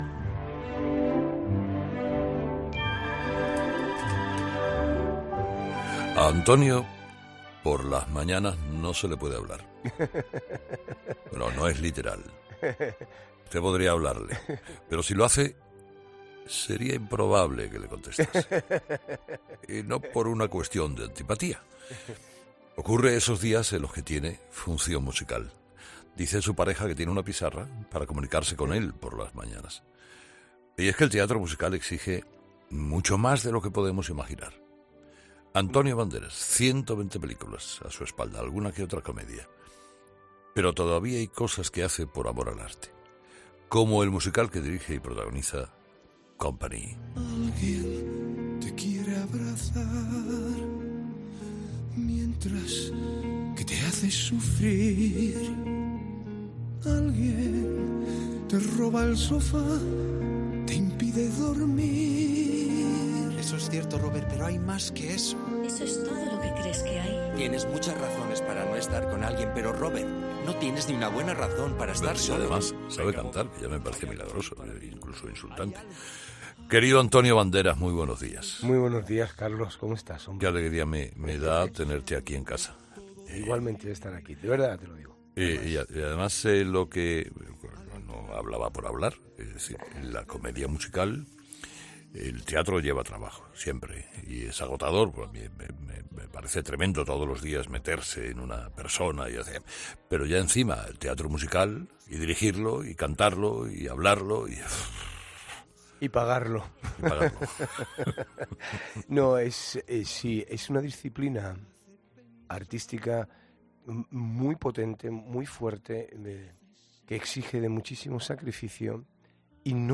Antonio Bandera ha sido entrevistado por Carlos Herrera en 'Herrera en COPE' por el estreno de 'Company'